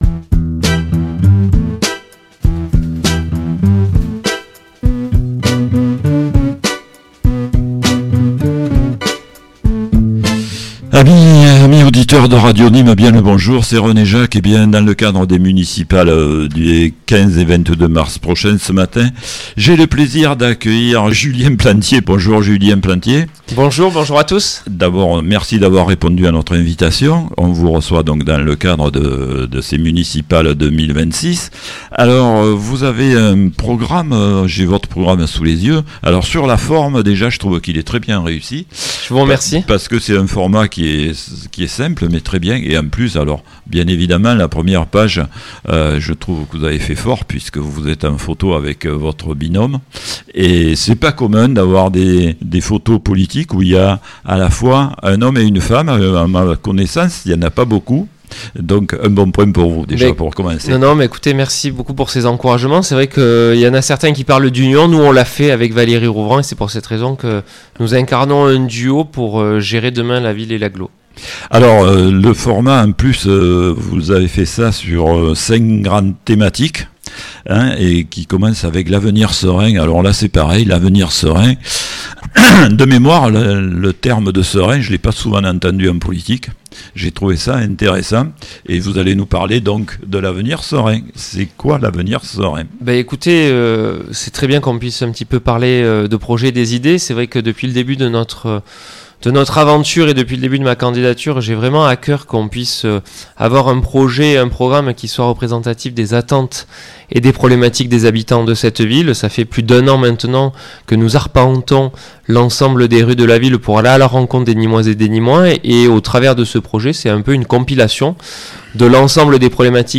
Municipales 2026 - Entretien avec Julien Plantier (L'avenir nîmois) - EMISSION DU 10 MARS
Émission spéciale élections municipales 2026